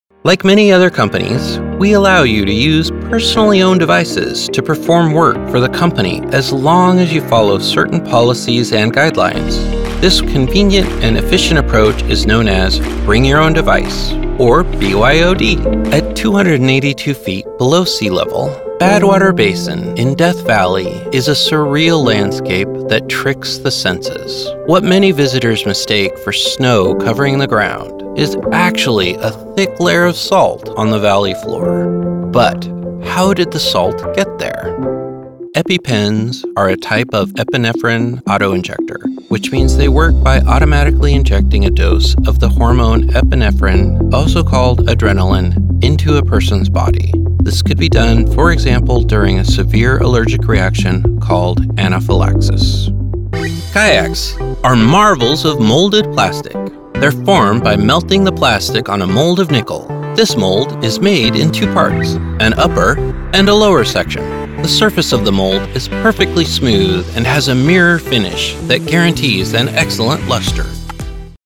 Location: California, USA Languages: english 123 english 123 Accents: standard us Voice Filters: VOICEOVER GENRE commercial commercial corporate narration corporate narration